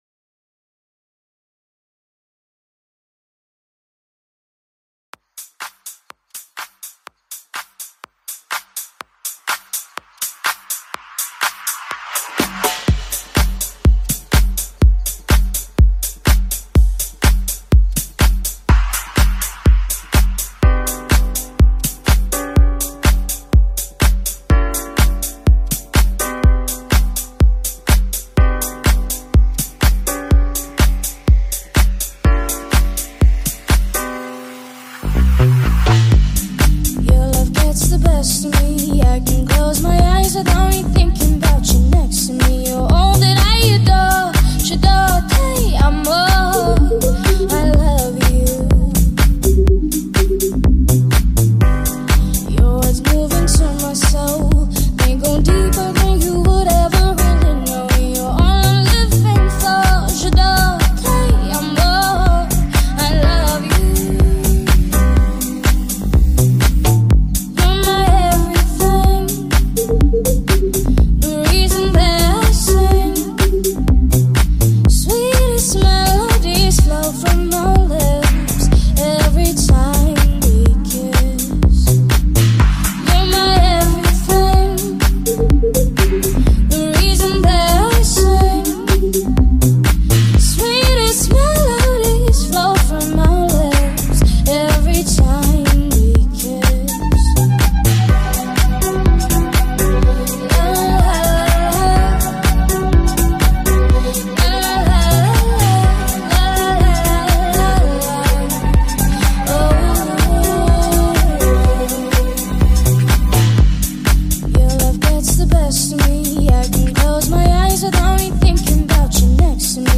Kwaito classics
Afrobeat